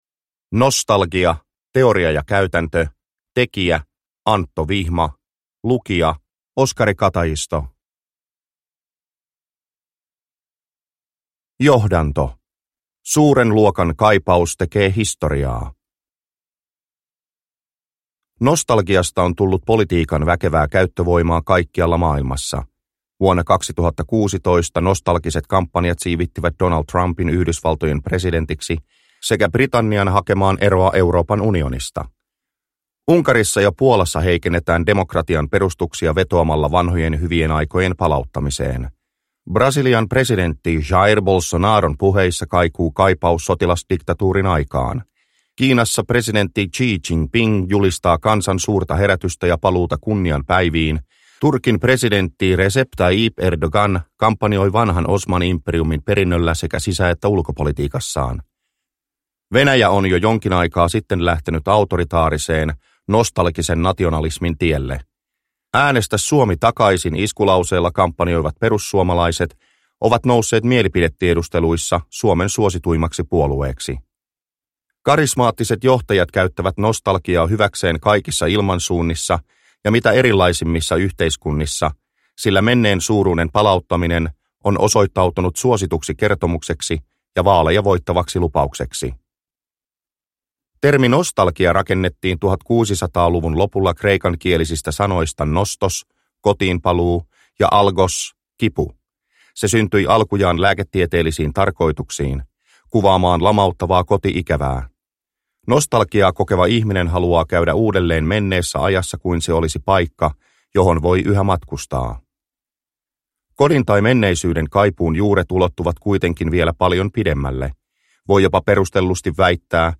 Nostalgia – Ljudbok – Laddas ner